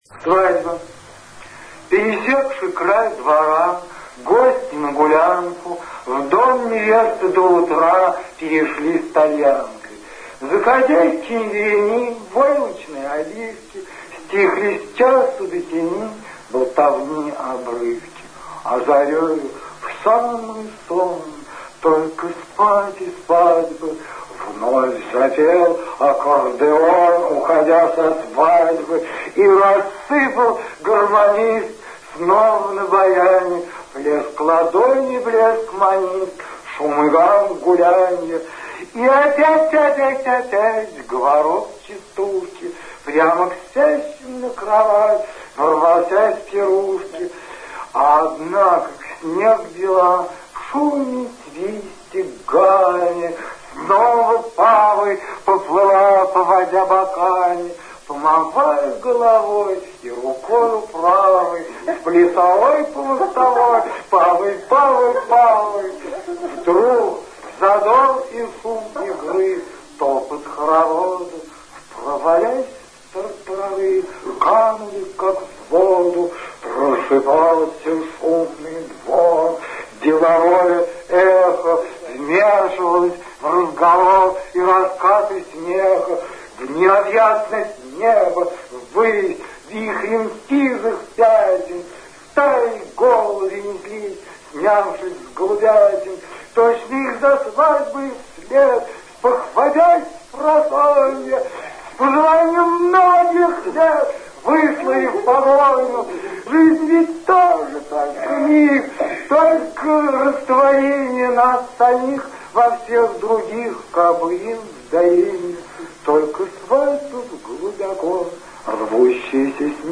Прослушать звуковой фрагмент (формат MP3) 490 kb Читает Борис Пастернак